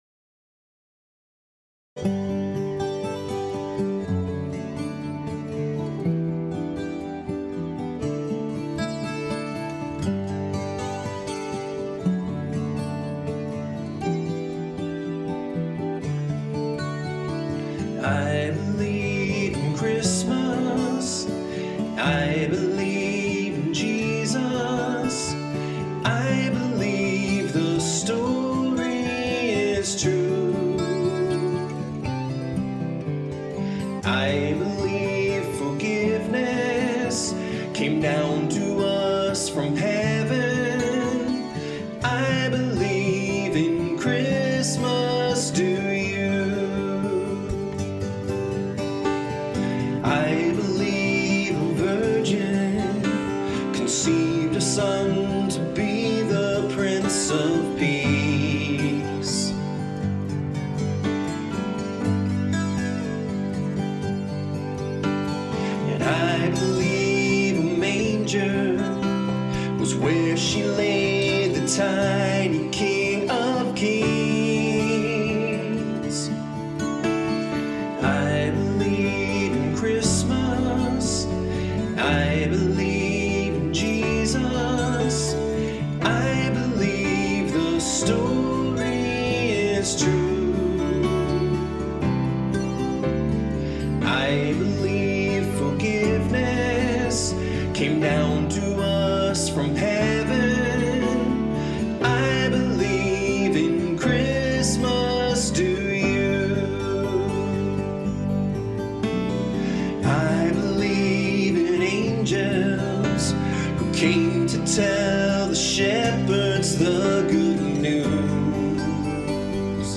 "I Believe in Christmas" - Vocals and Instrumental